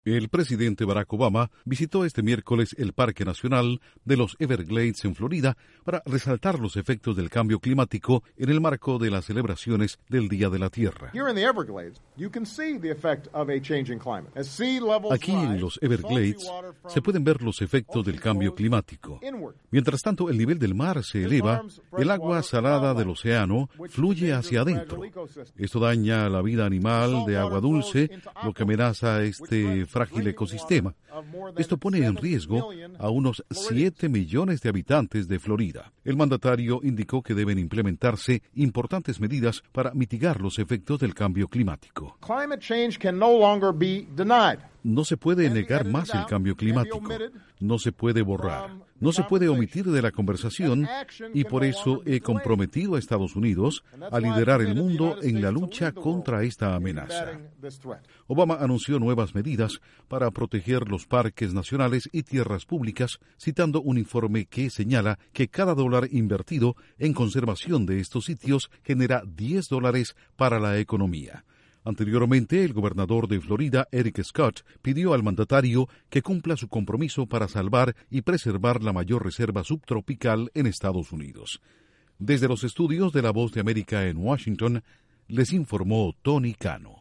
: El presidente Barack Obama visitó los "Everglades" en Florida, en el marco de las celebraciones por el Día de la Tierra, con el fin de elevar la alerta sobre el cambio climático. Informa desde los estudios de la Voz de América en Washington